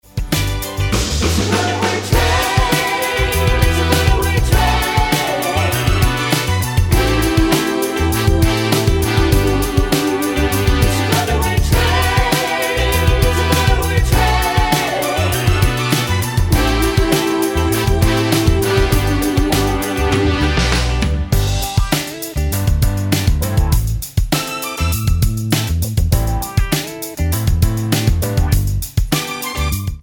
--> MP3 Demo abspielen...
Tonart:G mit Chor
Die besten Playbacks Instrumentals und Karaoke Versionen .